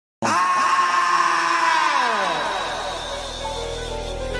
Scream Sound Effects MP3 Download Free - Quick Sounds